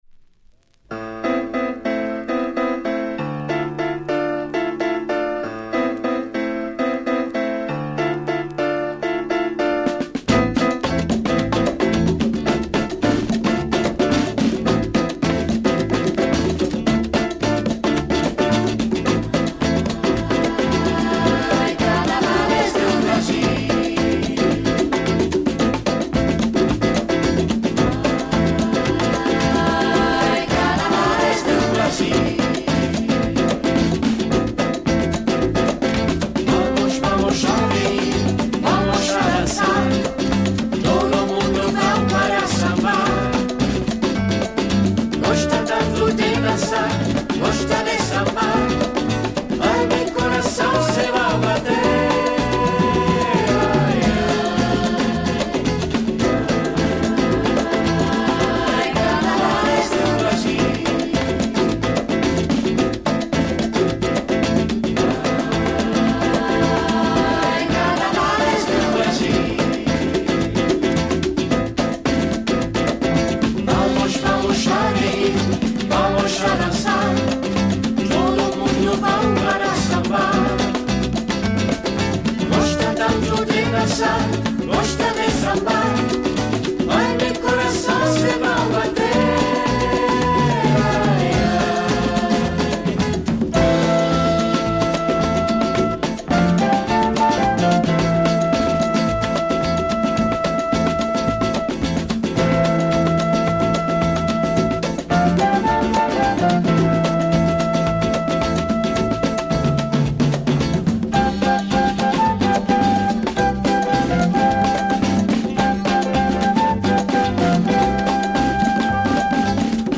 Latin groove
Classic Euro funk groove.